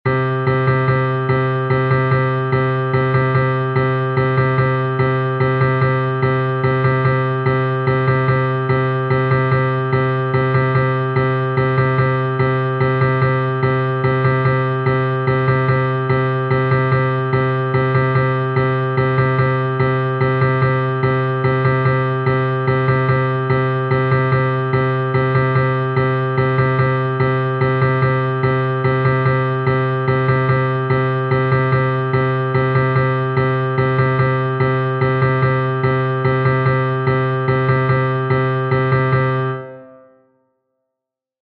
New rhythmic patterns
The bar of "The Game of Thrones" score is 6/8, that we already know.
ritmojuegotronos.mp3